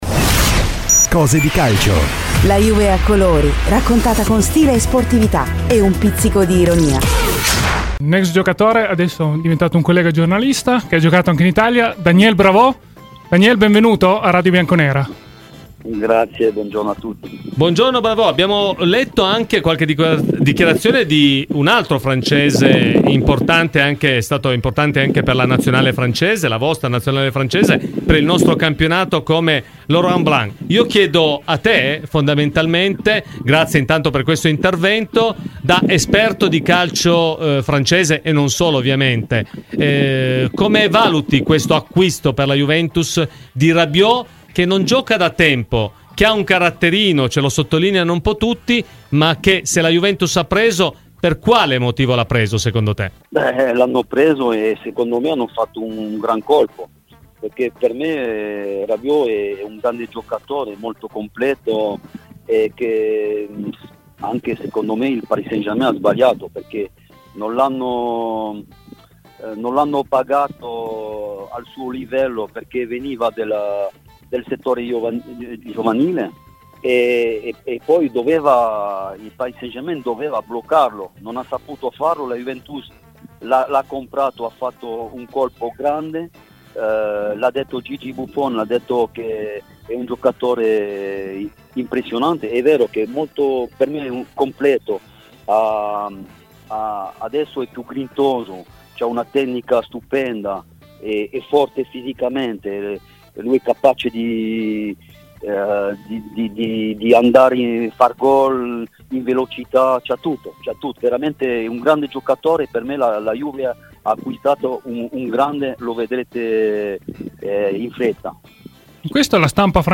Daniel Bravo ai microfoni di "Cose di Calcio" su Radio Bianconera.